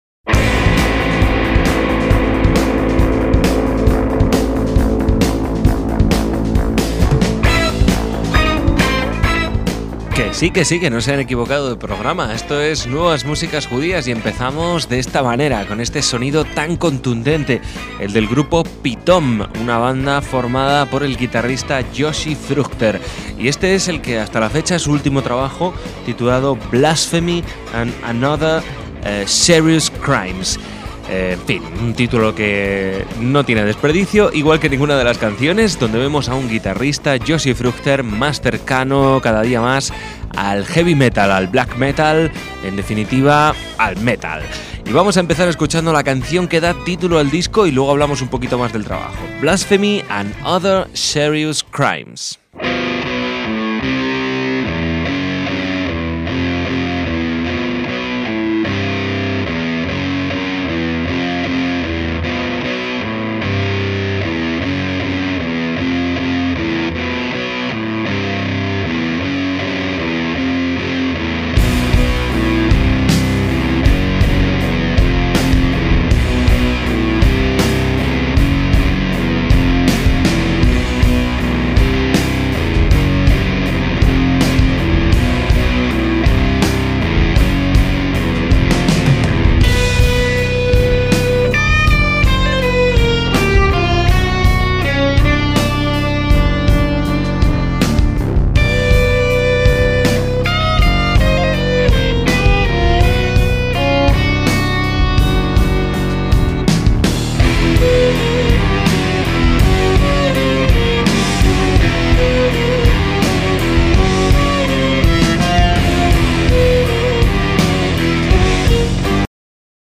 El rock de vanguardia se encuentra con la tradición judía
una banda instrumental judía arrasadora
guitarrista
composiciones pegadizas
Grabado y mezclado en el estudio
bajo
batería
violín y viola